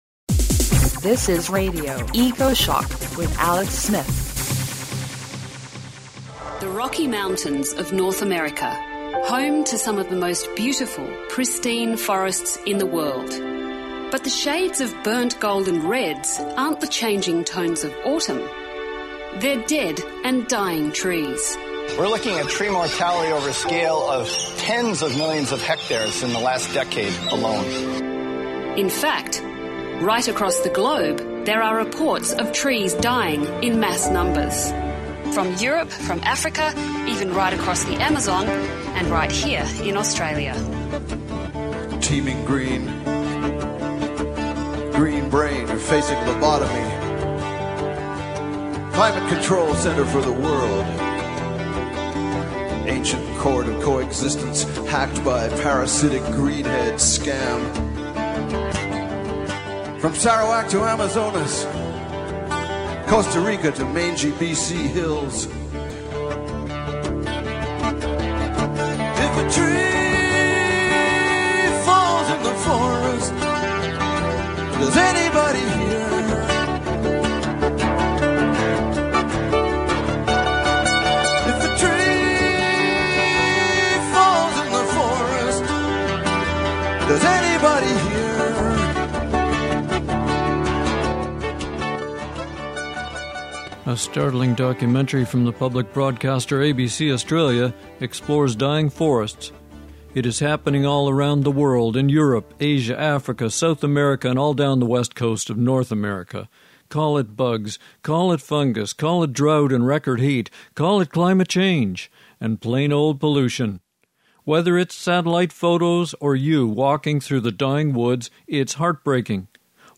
Ecoshock 120516 Lo-Fi - mp3 14M